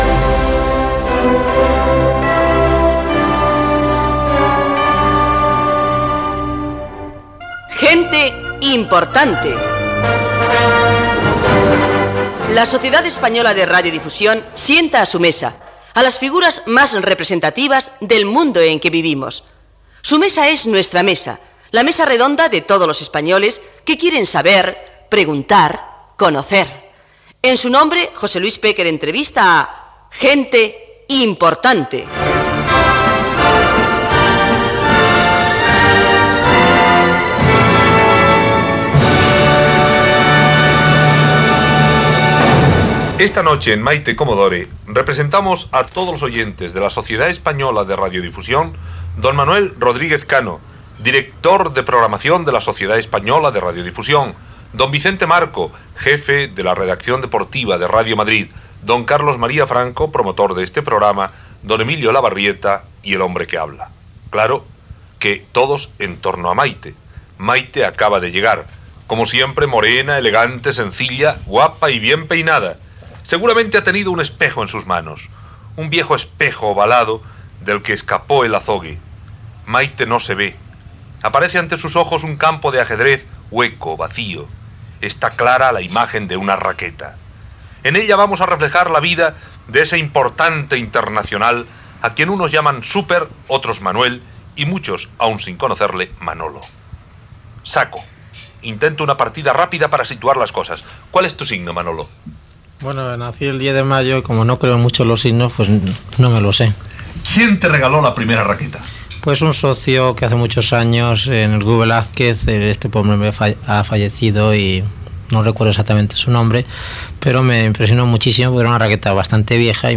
ad5b4c585e16f962697e1ec1719b3ff2928a895f.mp3 Títol Cadena SER Emissora Ràdio Barcelona Cadena SER Titularitat Privada estatal Nom programa Gente importante Descripció Careta del programa, equip, entrevista al tennista Manolo Santana.